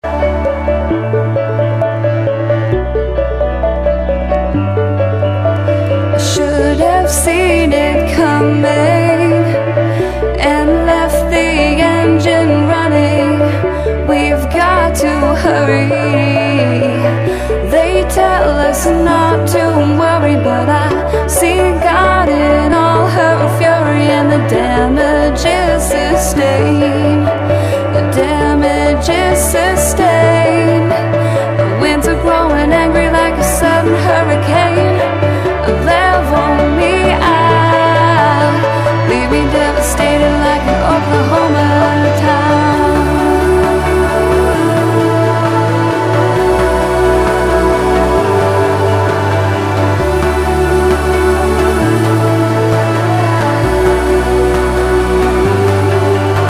• Качество: 128, Stereo
красивые
женский вокал
спокойные
пианино